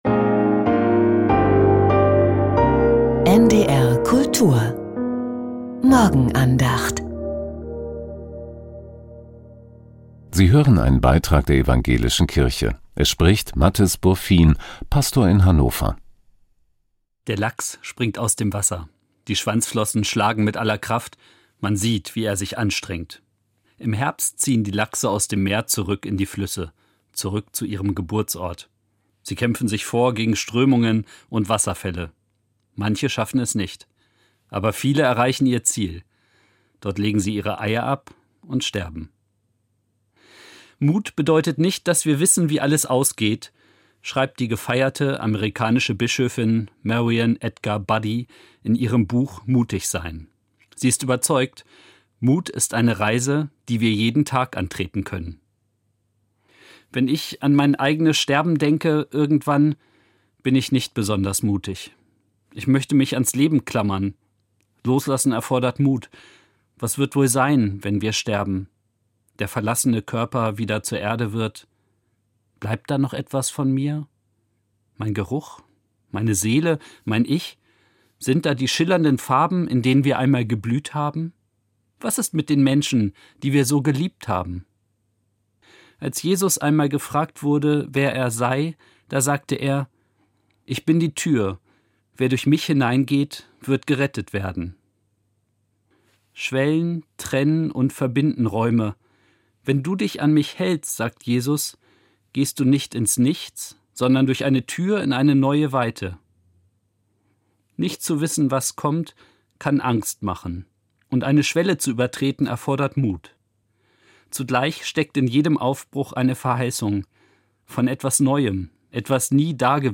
Vom Mut im Sterben ~ Die Morgenandacht bei NDR Kultur Podcast